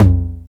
TR909TOM.wav